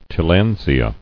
[til·land·si·a]